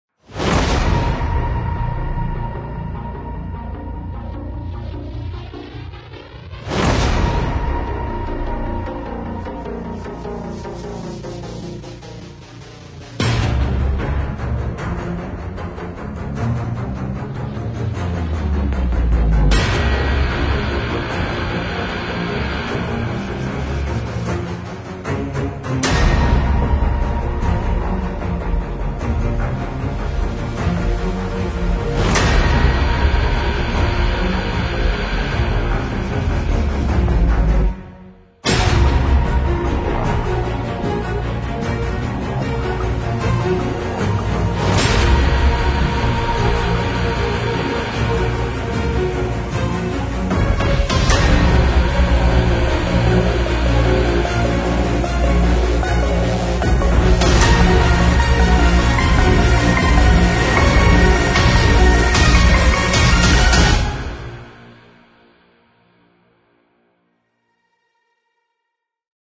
描述：管弦乐史诗混合音乐与管弦乐，铜管乐，合成器，SFX，升起，击打，打击乐，角和史诗鼓。